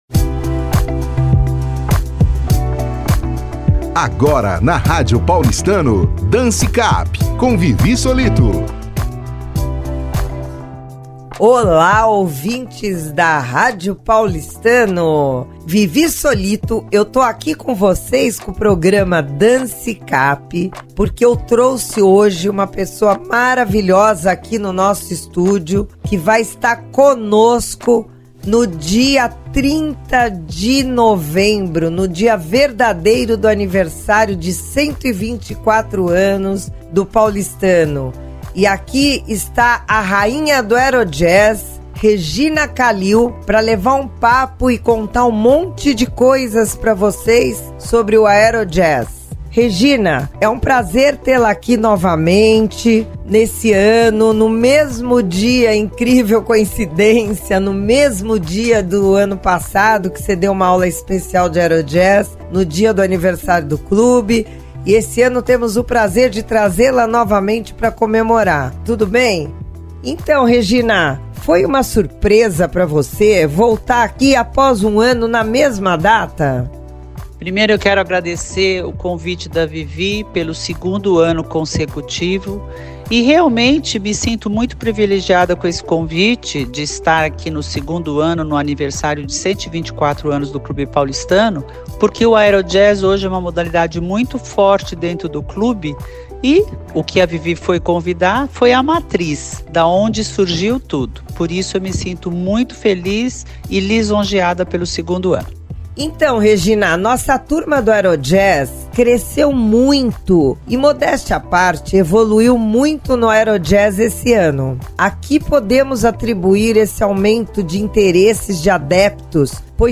DANCE CAP – BATE-PAPO